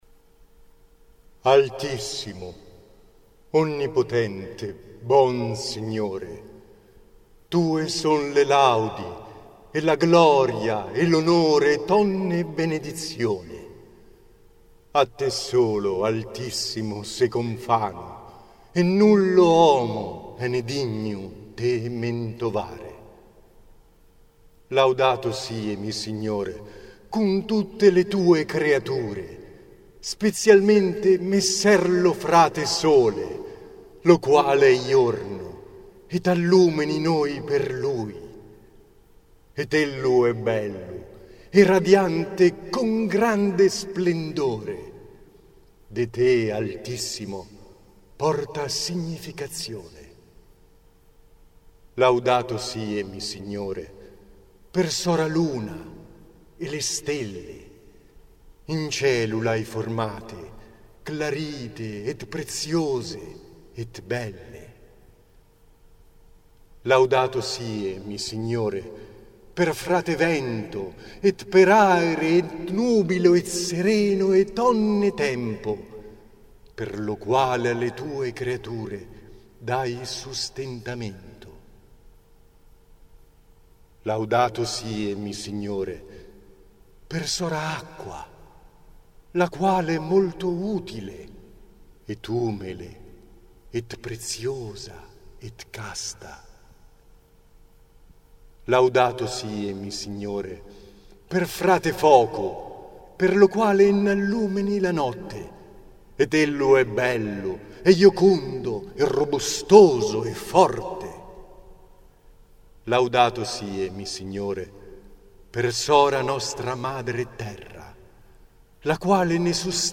La sera del 3 Ottobre 2011, nella chiesa di Sant'Antonio di Viareggio,
in occasione della cerimonia del Transito di San Francesco,
ho avuto l'onore di recitare il suo bellissimo "Cantico delle Creature".